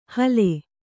♪ hale